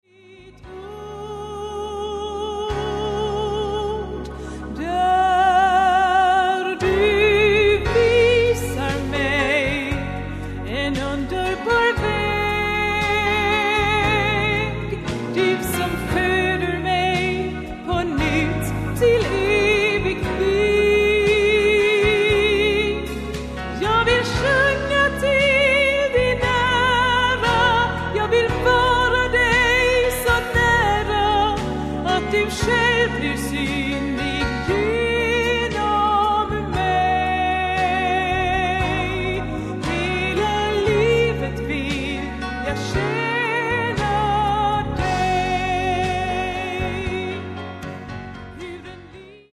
Dom spelade även klaviatur och orgel.